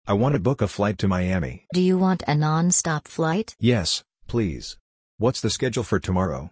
💬 Interactive Sample Conversations